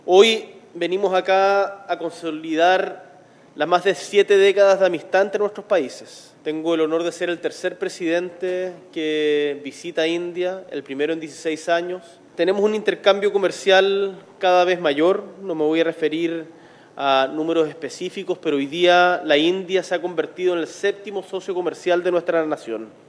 Boric y Modi entregaron una declaración conjunta este martes, donde el presidente chileno dijo que “tengo el honor de ser el tercer presidente que visita India, el primero en 16 años. Tenemos un intercambio comercial cada vez mayor”.